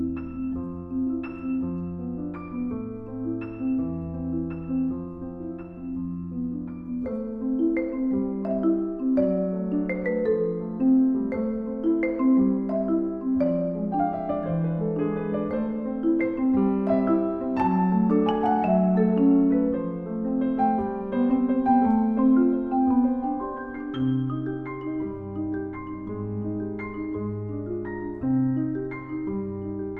perc. & chant